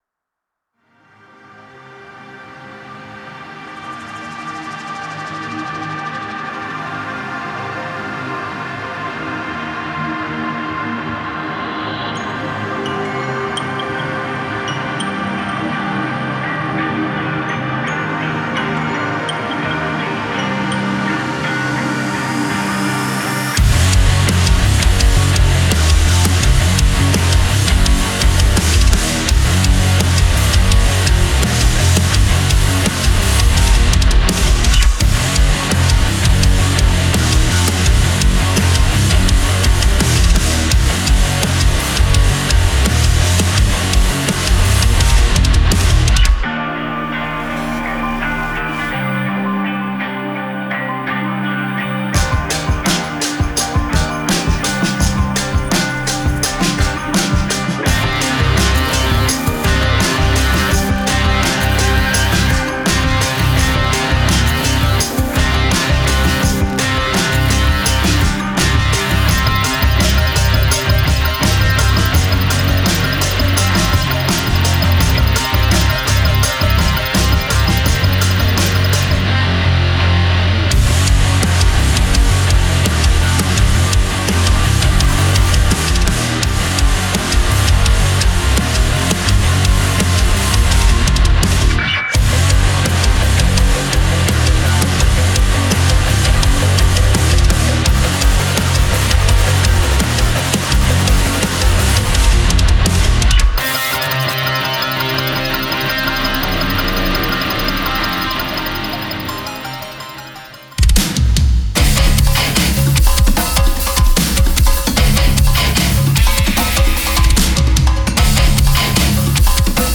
Genre:Rock
トラックに広がりとドラマ性、そして没入感を与えます。
デモサウンドはコチラ↓